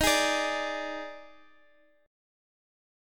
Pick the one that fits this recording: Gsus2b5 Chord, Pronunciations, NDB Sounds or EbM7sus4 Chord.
EbM7sus4 Chord